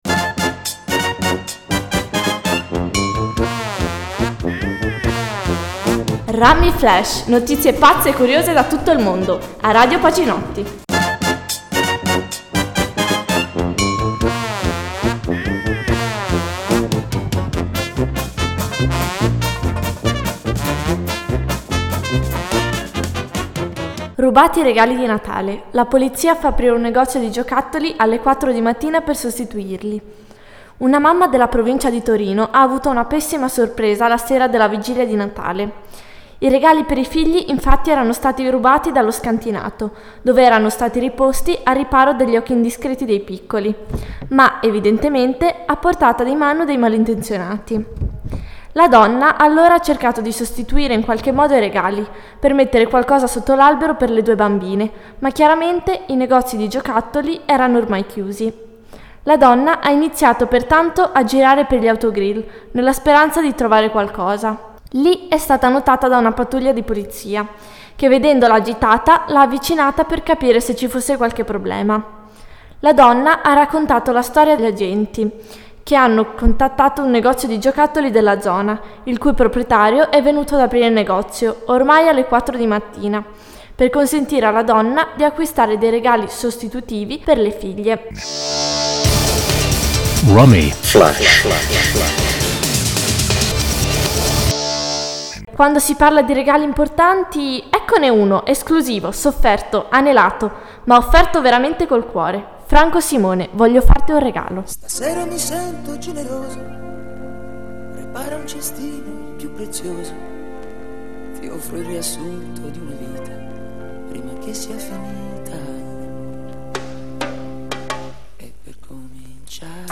Notizie curiose e brano di musica con relazione alla notizia